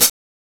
Index of /m8-backup/M8/Samples/Fairlight CMI/IIX/CYMBALS
HHCLOSD.WAV